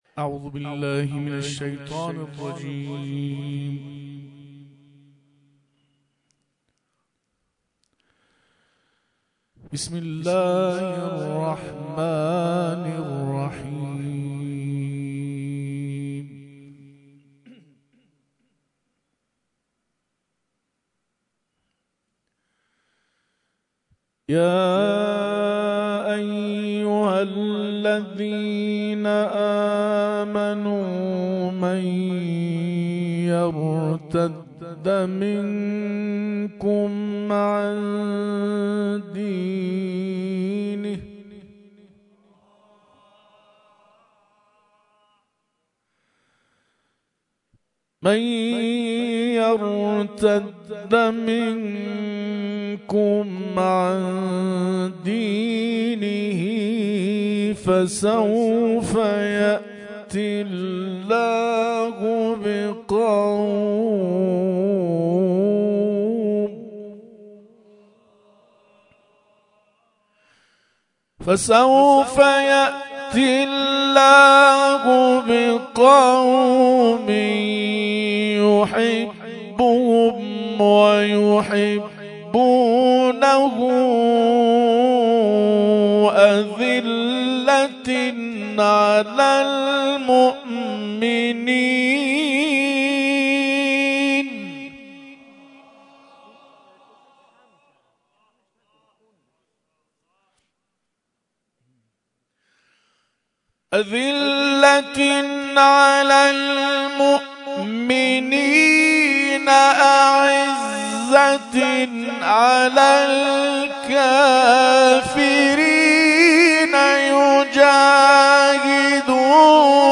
گروه جهادی برای ایران همزمان با سالگرد شهادت سردار شهید حاج قاسم سلیمانی به مدت 10 شب محافل انس با قرآن با حضور قاریان ملی و بین‌المللی برگزار می‌کند.